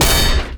impact_projectile_metal_004.wav